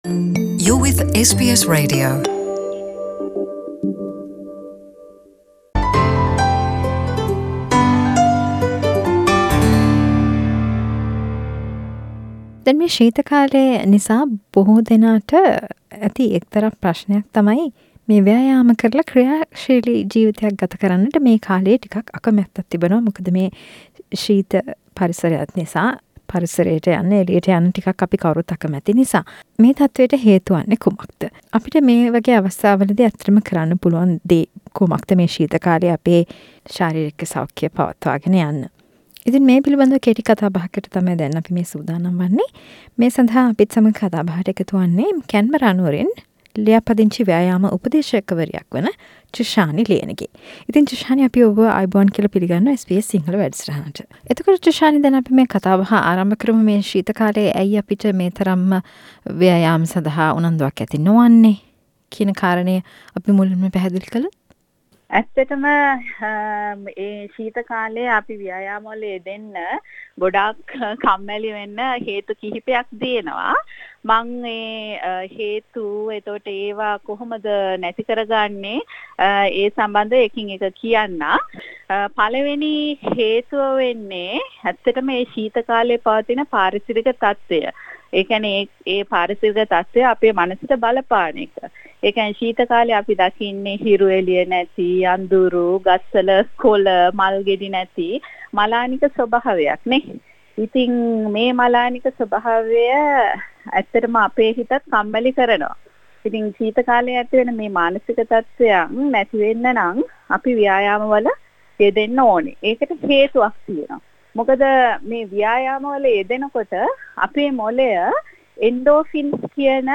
කතා බහක්